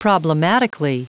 Prononciation du mot problematically en anglais (fichier audio)
Prononciation du mot : problematically